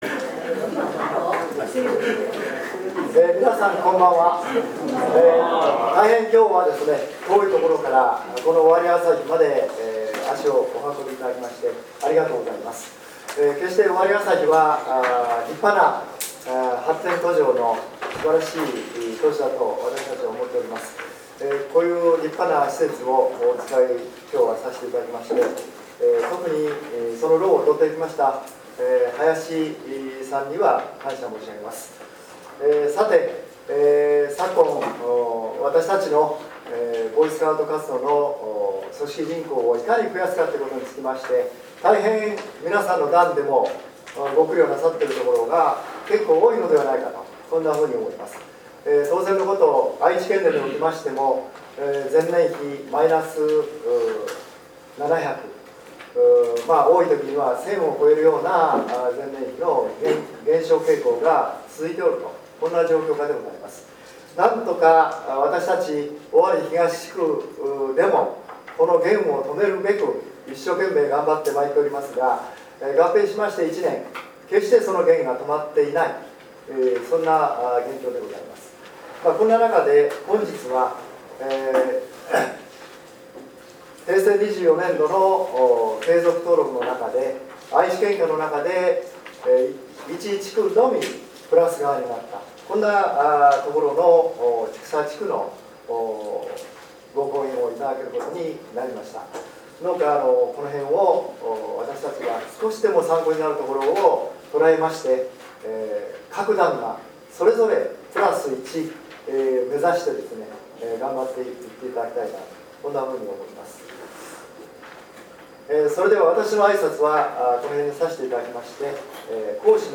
H24.9.23 組織委員会講演会